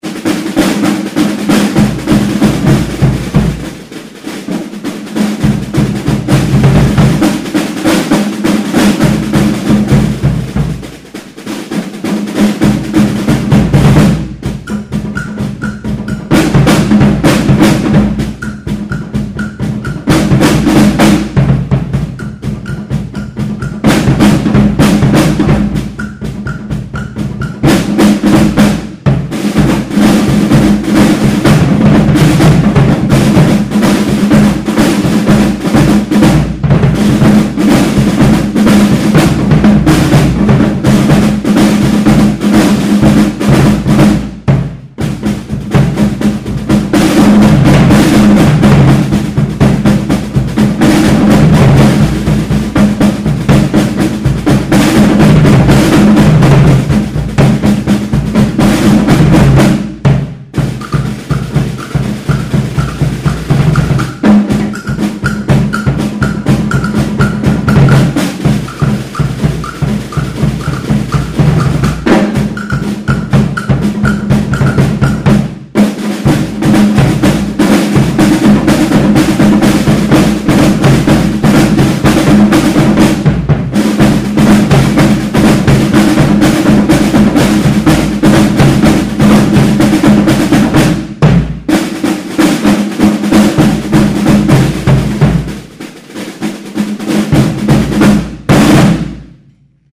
Junior Percussion